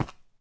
stone3.ogg